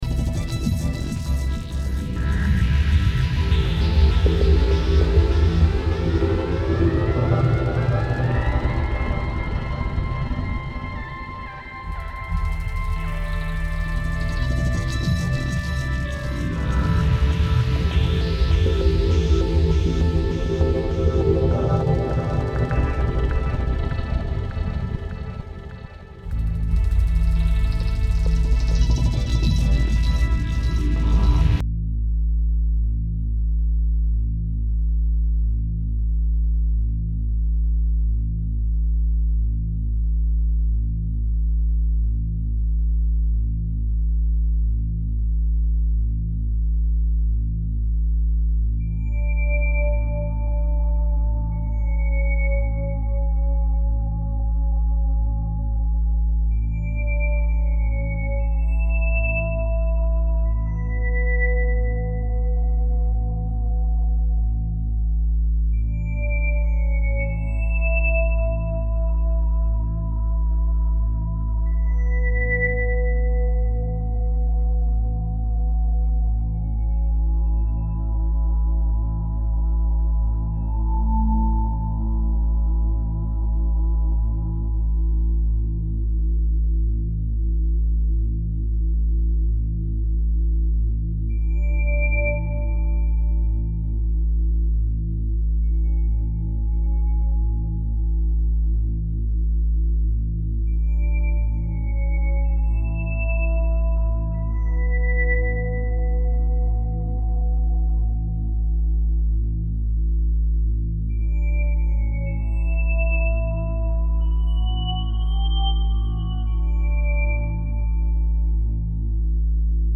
These were improvisations all recorded in late 2005.
Sapphire Facet #4 was originally called “house…” and It was an experiment in incorporating ‘granular synthesis” into a drone piece. it starts out okay but I had problems with the lead synth later in the piece.